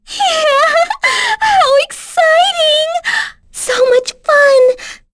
Pansirone-Vox_Skill2.wav